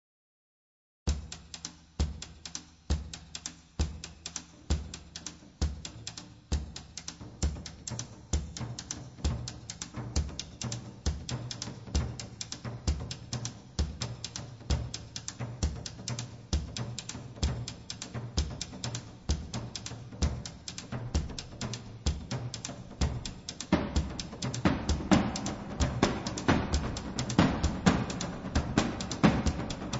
atupan drums
berimbau
brekete drums,vocals
congas, wea flute
cowbells, cabasa